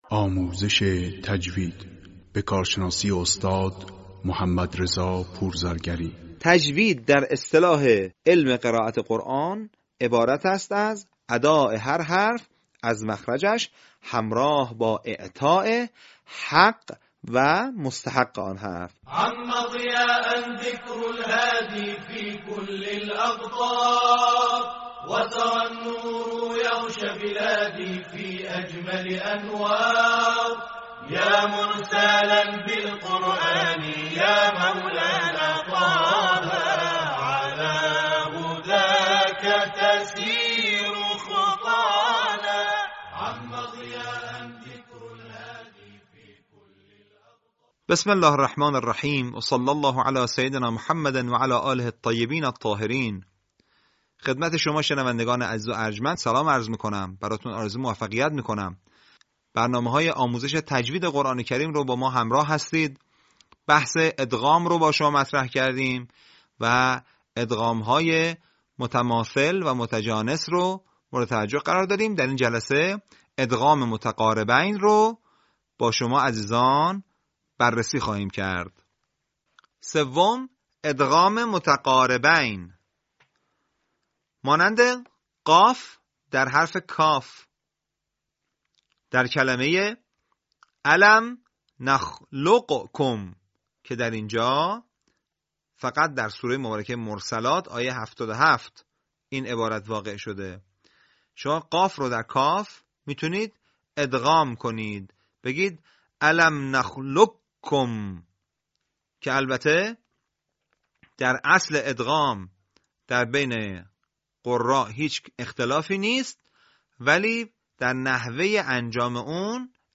صوت | آموزش ادغام متقاربین